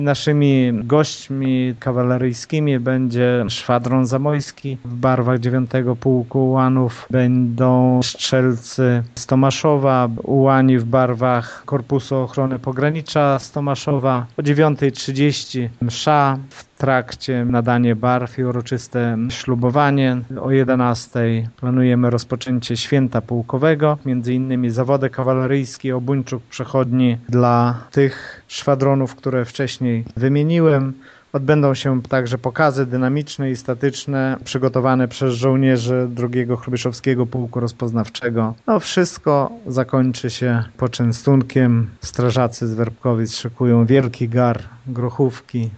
Uroczystości, podczas których odbędzie się oficjalne nadanie barw szwadronowi, rozpoczną się w najbliższą niedzielę 7 października o 8.30 w amfiteatrze w Werbkowicach - informuje prezes stowarzyszenia, wójt Gminy Mircze Lech Szopiński: